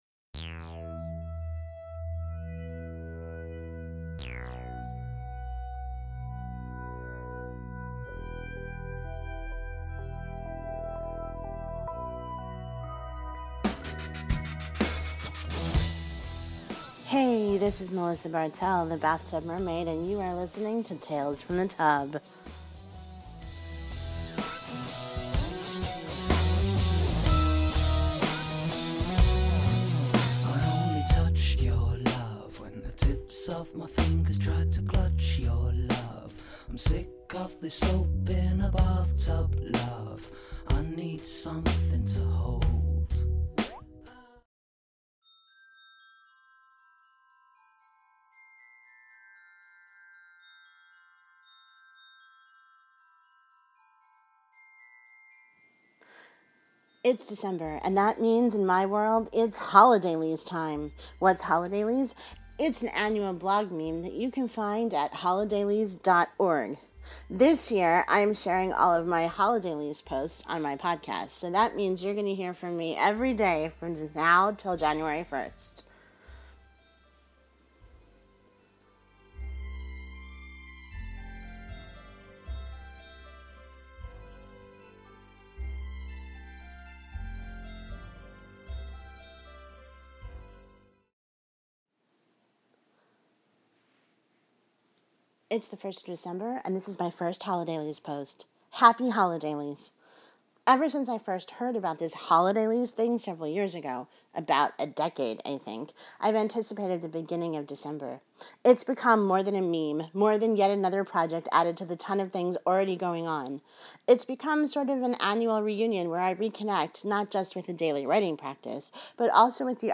Music for The Bathtub Mermaid is provided by Mevio’s Music Alley, a great resource for podsafe music. The standard opening song is “Soap in a Bathtub,” by Stoney.
Additional music used for the Holidailies project is “A Podcast Christmas Theme” by Tom Shad, and “Village Song” composed by David Popper and performed by Cello Journey.